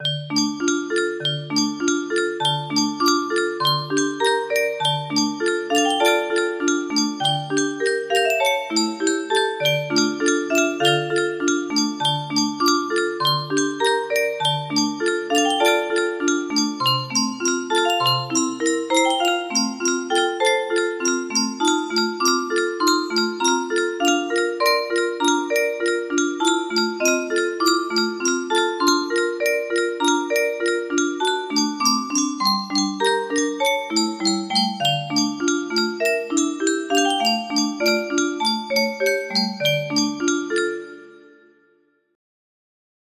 LOMEJONDELOMEJON music box melody
Full range 60